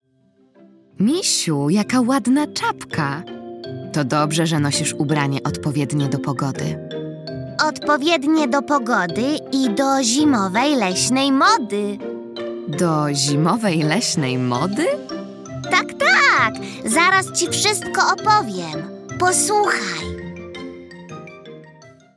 2_narracja.mp3